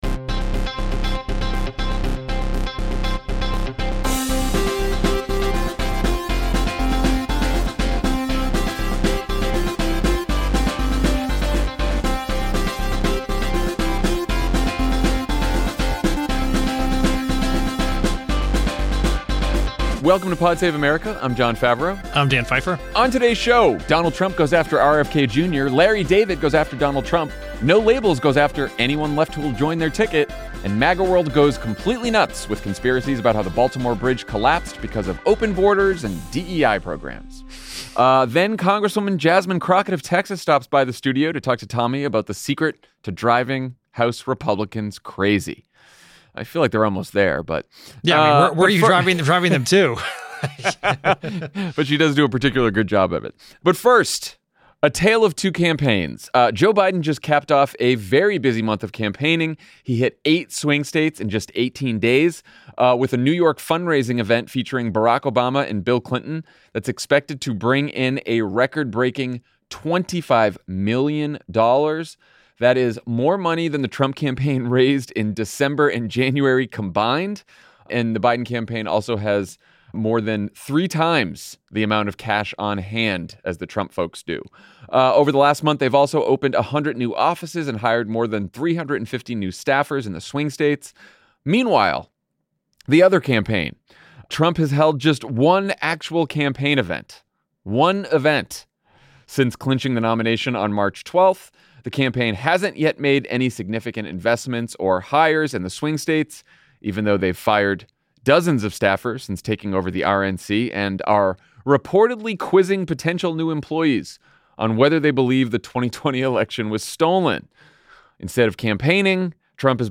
MAGA world goes nuts with conspiracy theories about how the Francis Scott Key bridge collapsed because of DEI programs and open borders. And later, Congresswoman Jasmine Crockett of Texas stops by the studio